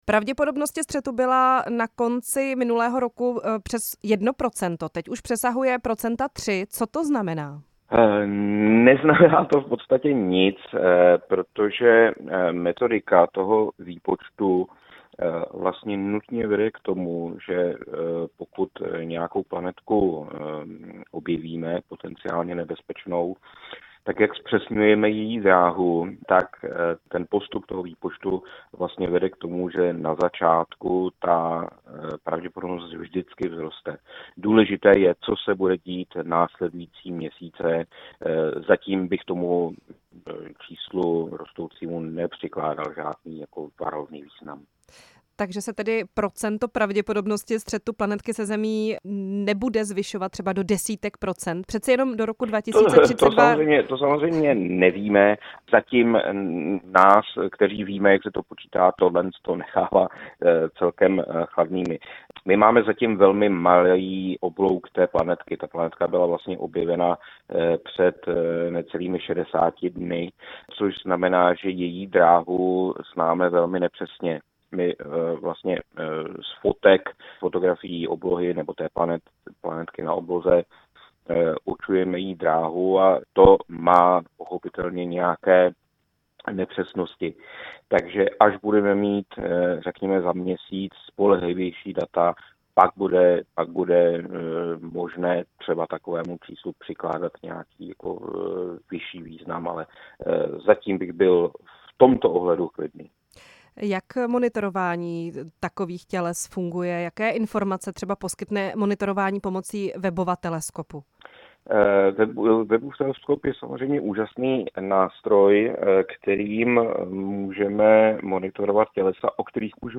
Ve vysílání RP